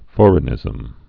(fôrĭ-nĭzəm, fŏr-)